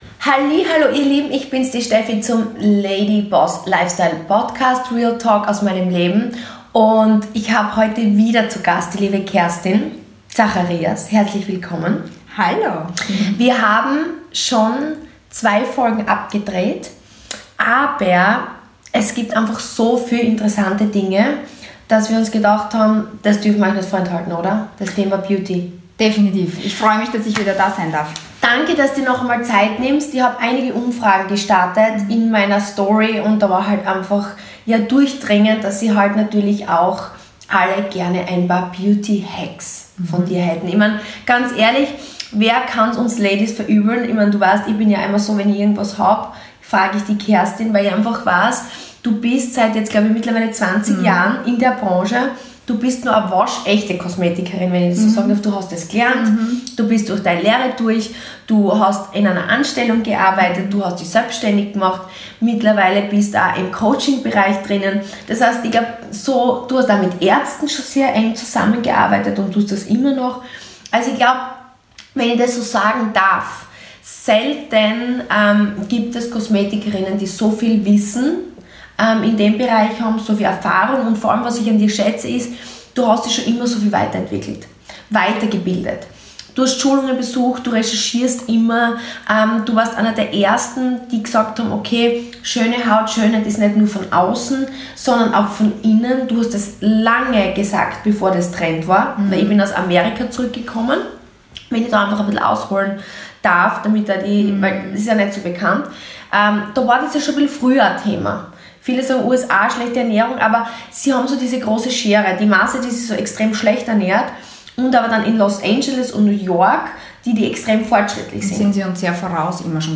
Interview
Außerdem sprechen wir so offen wie noch nie über unsere eigene Beauty Routinen.... und im Gespräch bei mir im Wohnzimmer verraten wir auch ein paar Secrets 🤫 Du erfährst wie du zu einem schöneren Hautbild gelangen kannst.